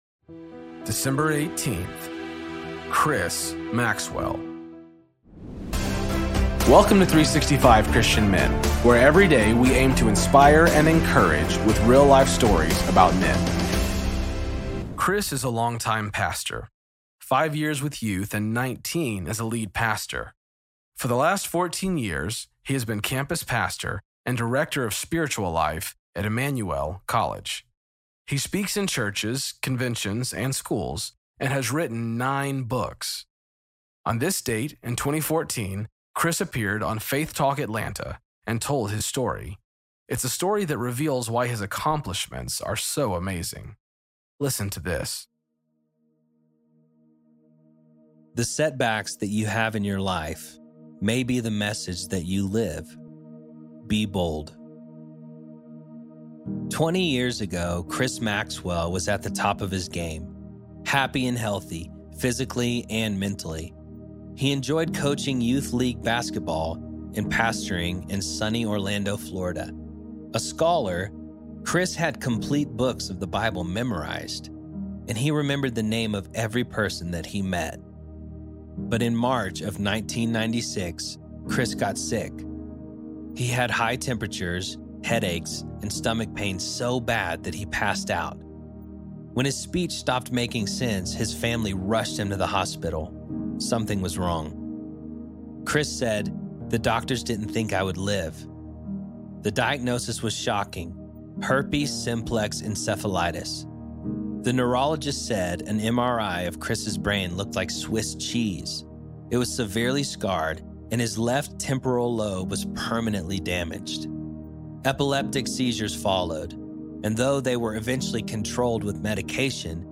Story read by: